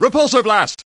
Play, download and share Repulsor Blast original sound button!!!!
iro_repulsorblast.mp3